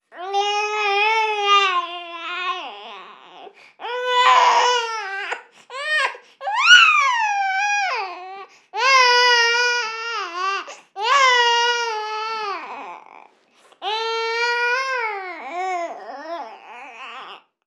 Llanto de un bebé
Sonidos: Voz humana